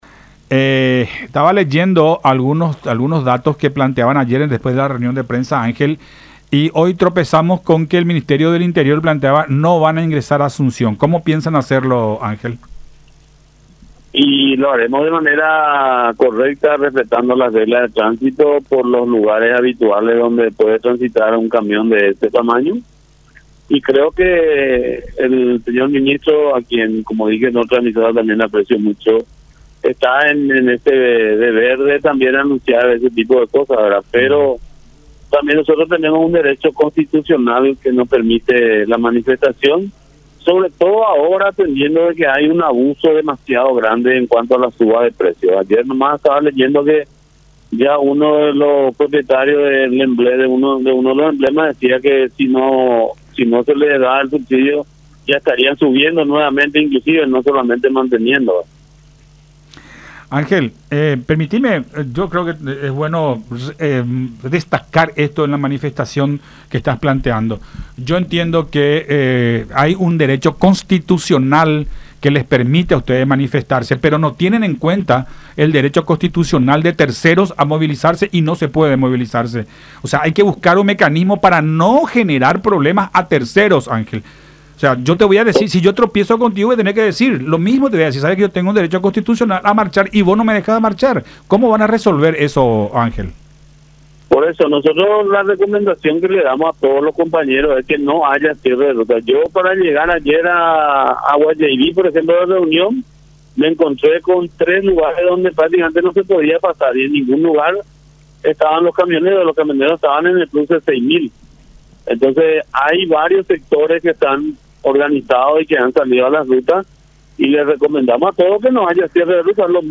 en charla con Todas Las Voces por La Unión.